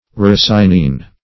Search Result for " ricinine" : The Collaborative International Dictionary of English v.0.48: Ricinine \Ric"i*nine\, n. [L. ricinus castor-oil plant.]
ricinine.mp3